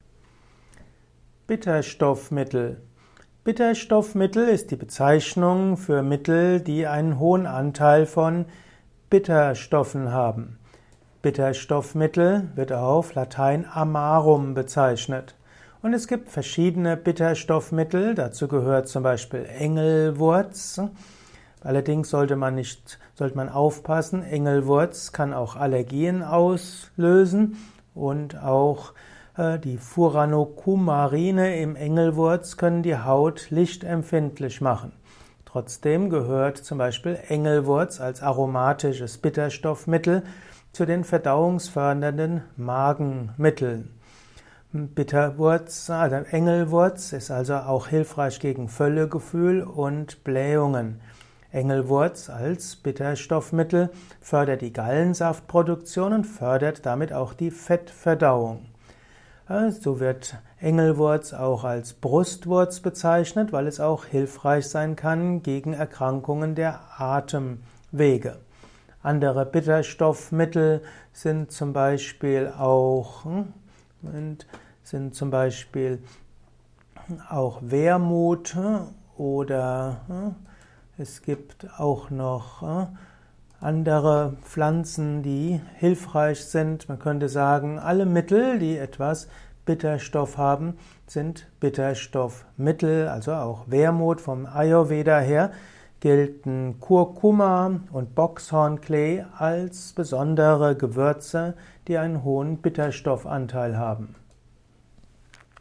Kompakte Informationen zu Bitterstoffmittel in diesem Kurzvortrag